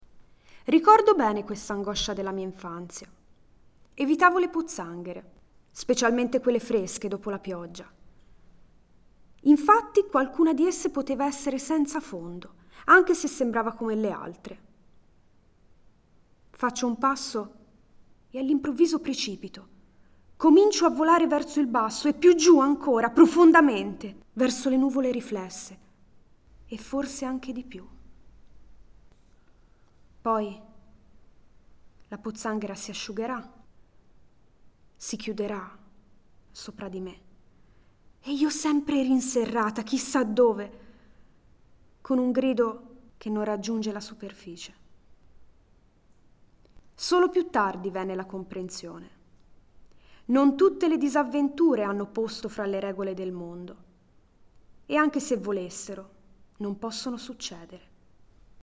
dallo spettacolo del 10 Luglio 2015
Nell’ascolto della recitazione degli attori, diventa esplicita anche un’altra caratteristica comune a molte delle sue poesie, cioè la loro teatralità intrinseca, che forse deriva proprio dall’immediatezza del loro stile: questo permette di interpretarle dando voce e vita direttamente agli stessi protagonisti delle poesie.